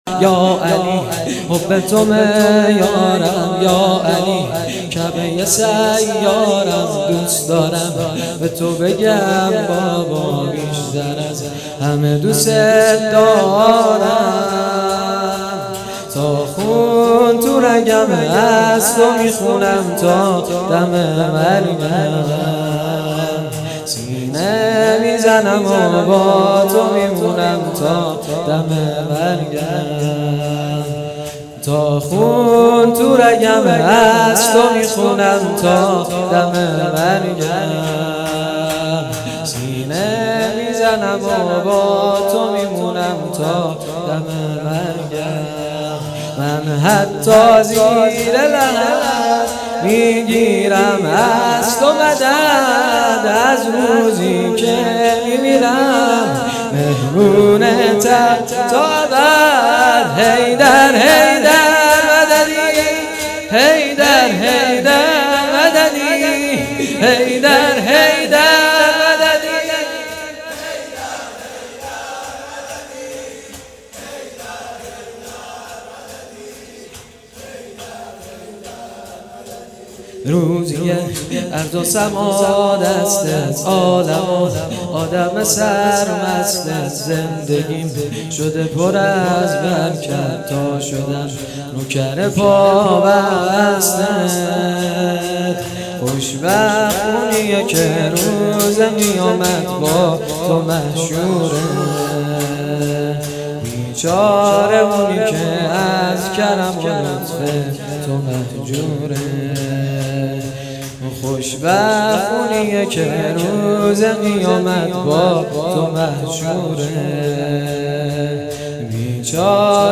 شهادت امام صادق علیه السلام-شب اول